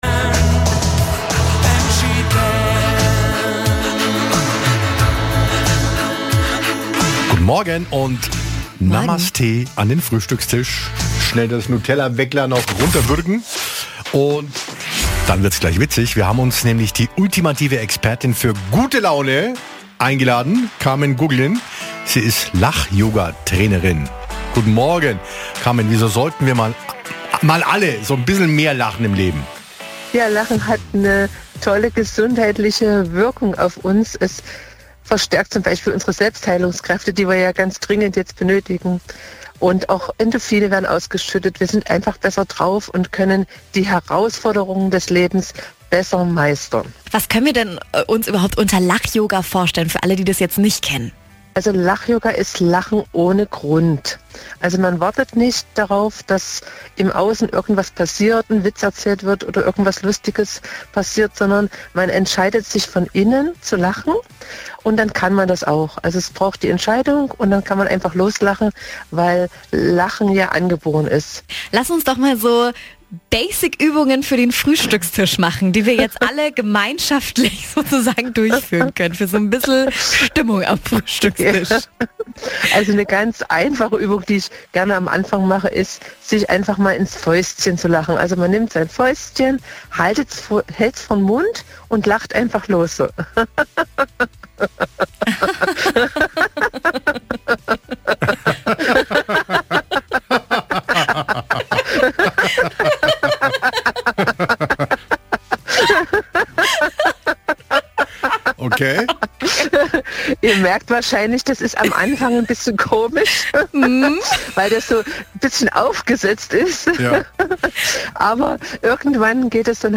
Interview mit Radio Charivari